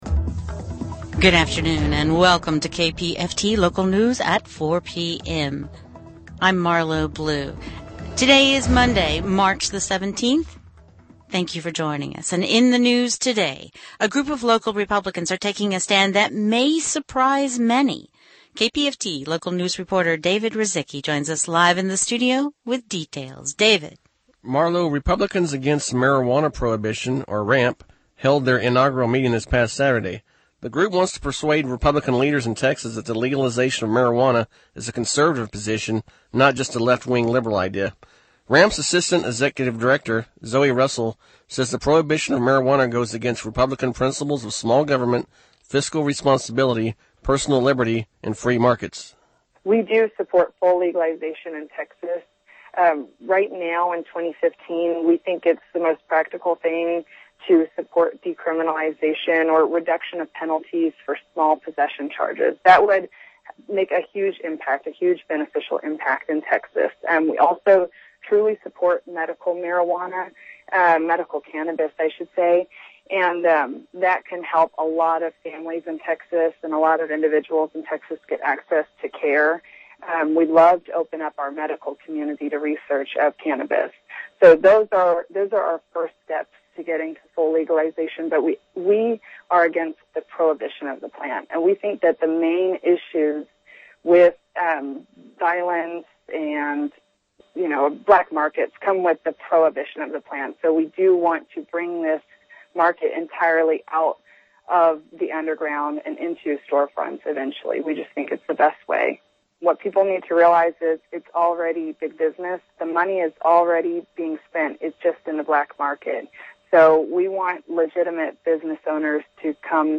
KPFT-Coverage.mp3